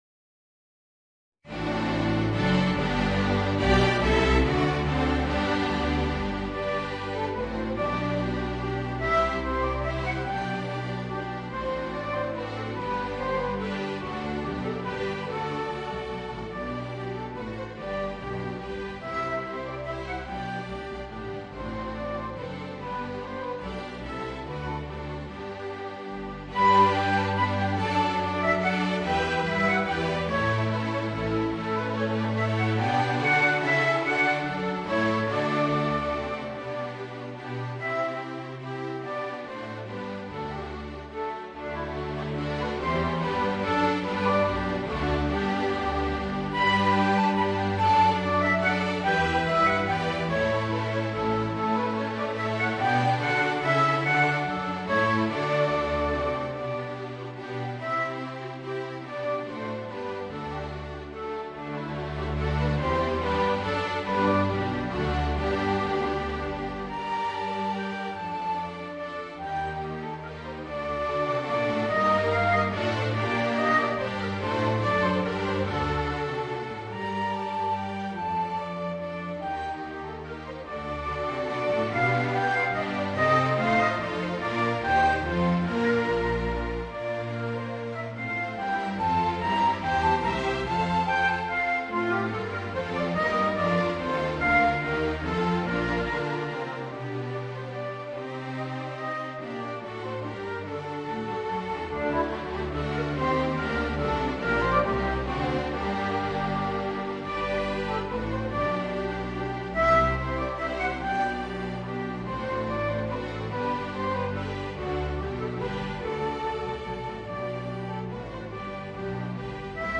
Voicing: Violoncello and String Orchestra